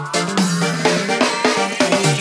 Друзья, помогите, пожалуйста, найти эти сбивки.
Друзья, подскажите пожалуйста, откуда взяты эти замечательные (прикрепил аудио) "латинские", сбивочки?
Что за драм-машинка или, может, библа какая-то популярная, потому что во многих песнях я встречал эти сбивки...